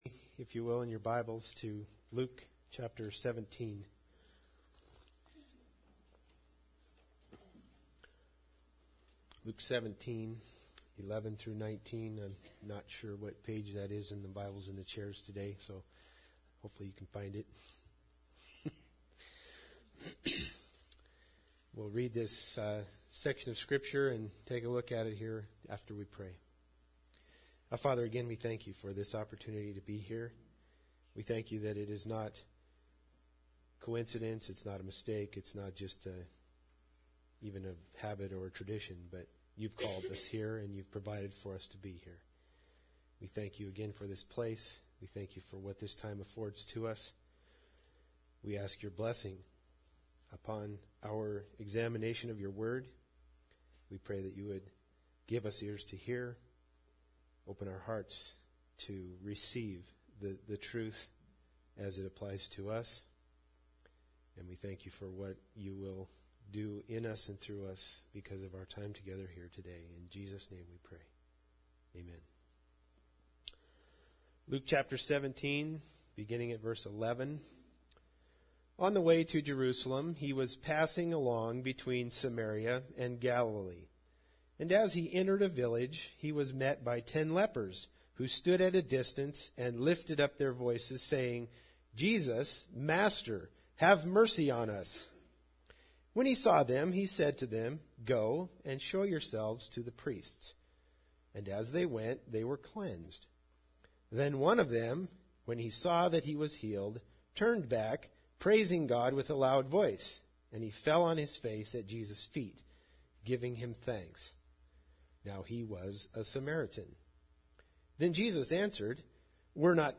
Luke 17:11-19 Service Type: Special Service Bible Text